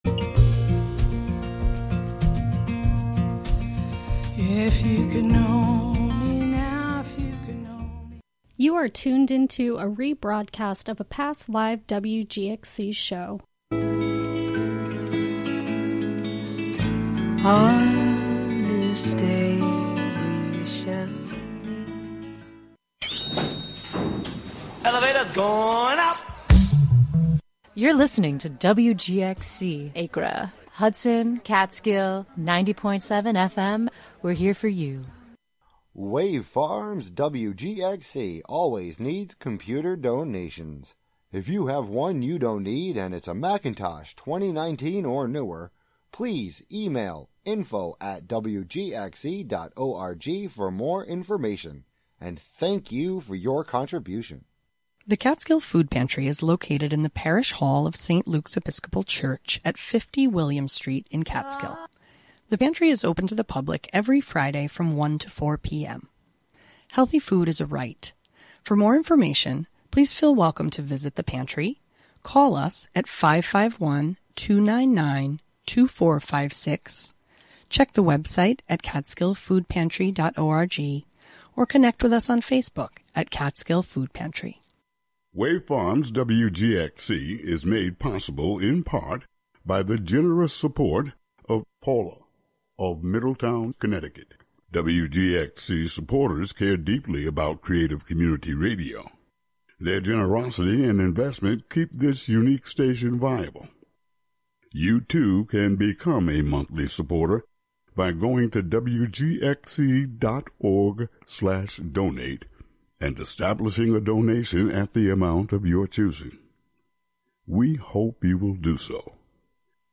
Through music, song, and story, embodied wisdom is shared and activated to maintain the fluidity of a functional, balanced, and regenerative society.